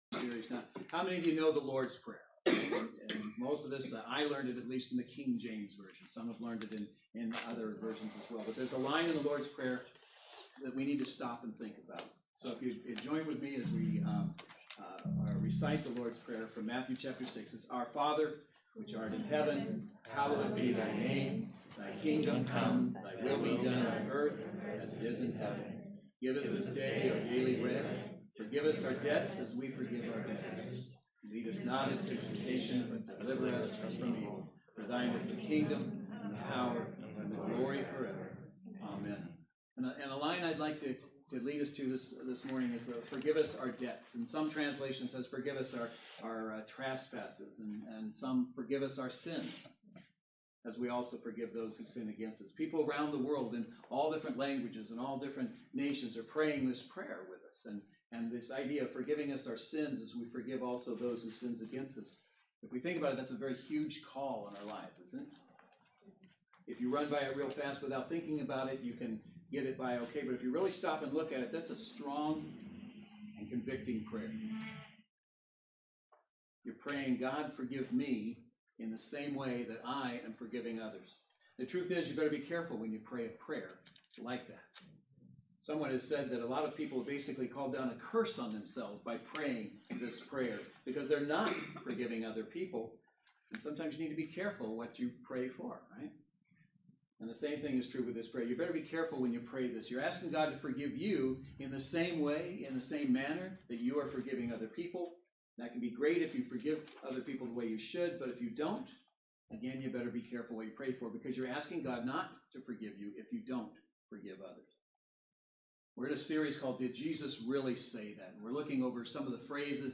Passage: Matthew 6:9-13 Service Type: Saturday Worship Service Bible Text: Matthew 6:9-13Series: Did Jesus Really Say That?